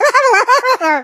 gene_vo_09.ogg